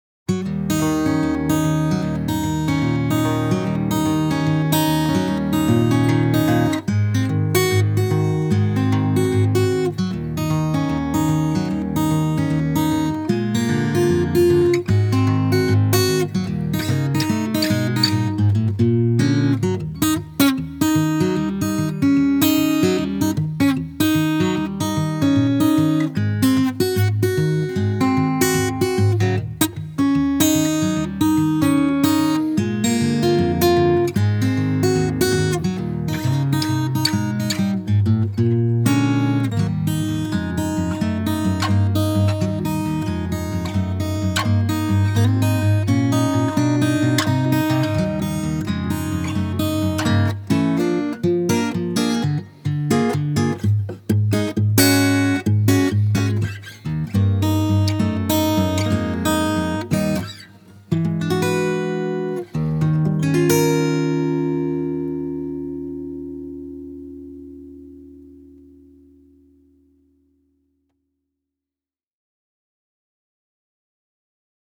Тип альбома: Студийный
Жанр: Blues-Rock